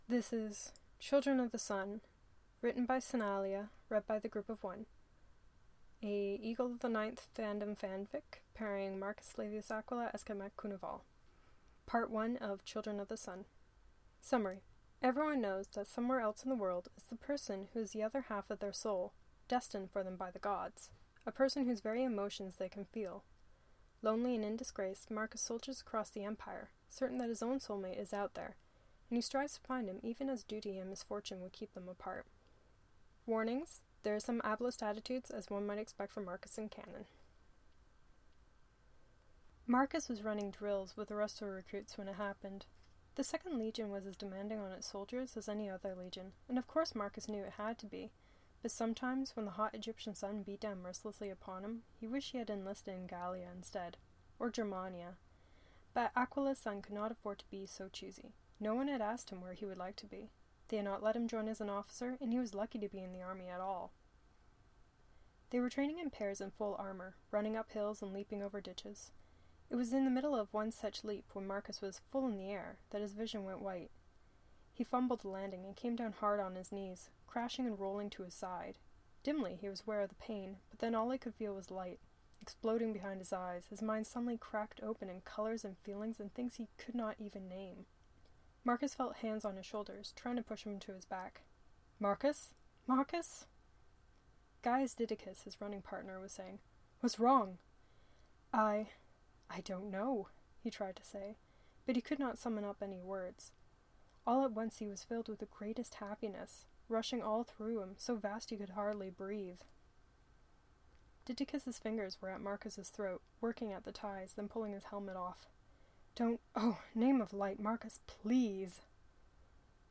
Children of the Sun: the Podfic